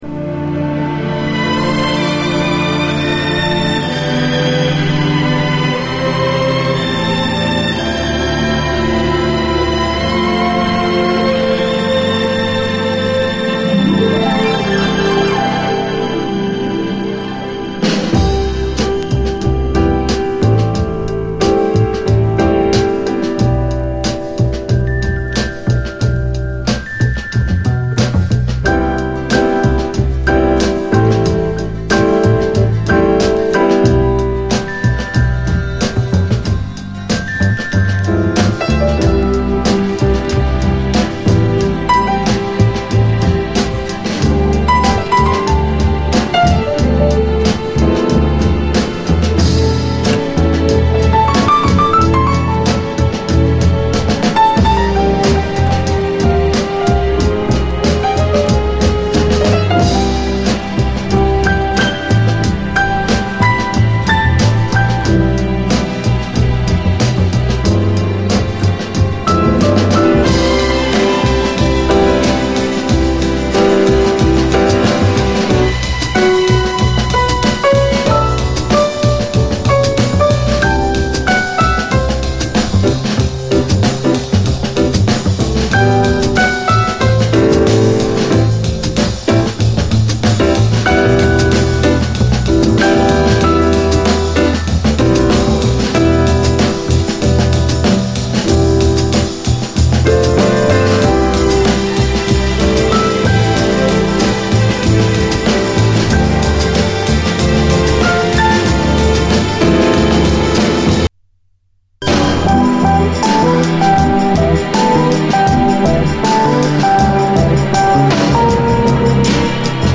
JAZZ FUNK / SOUL JAZZ, JAZZ
ストリングスやピアノは美しく、しかし、ビートはダーティーなまでに打っています。